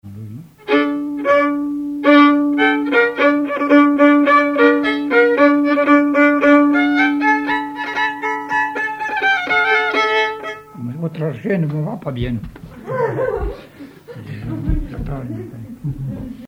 musique traditionnelle
violoneux, violon
danse : polka
Pièce musicale inédite